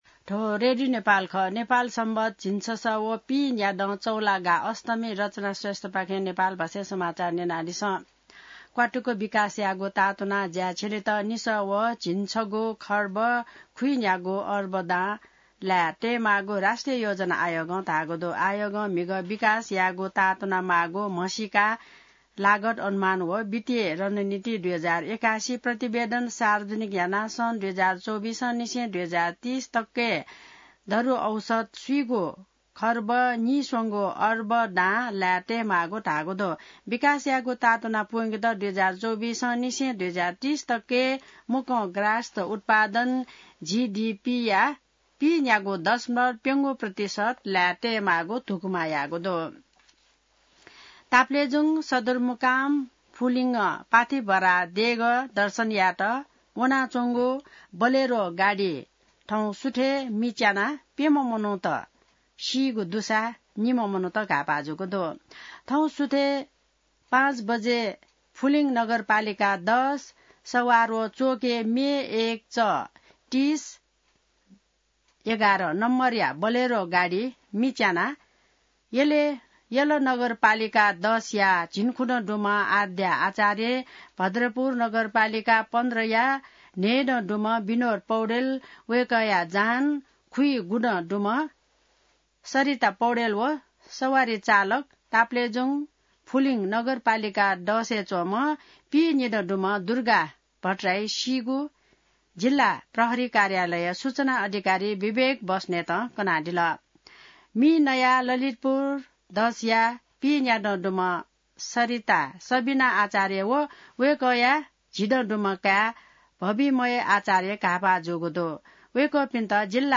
नेपाल भाषामा समाचार : ८ वैशाख , २०८२